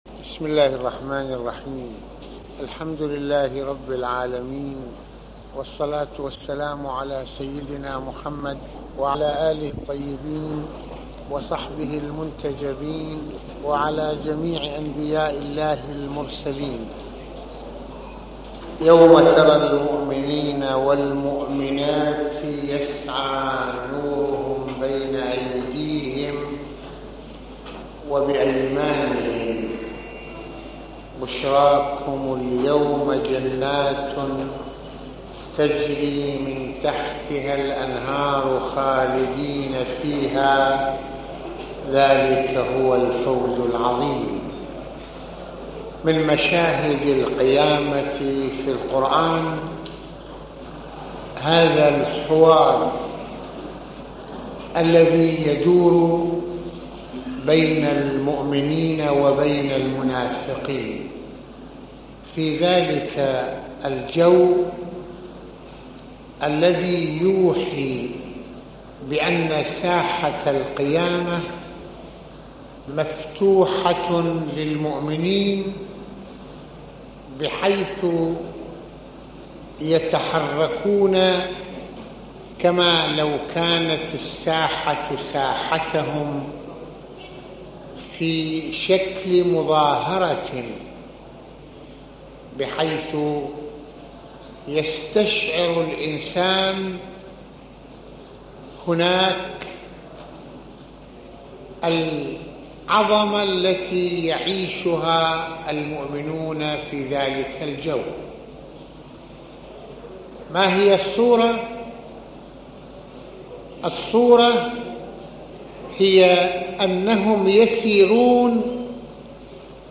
- يتحدث المرجع السيد محمد حسين فضل الله (رض) في هذه المحاضرة القرآ نية عن جو الحوار بين المؤمنين والمنافقين وما يوحيه ذلك من دلالات تنعكس على حركة الإنسان ومشاعره ، فالمؤمنون يوم القيامة يجري النور من بين أيديهم جزاء على أعمالهم الصالحة و بما خصهم الله به من إشراقة العقل والقلب والإيمان ..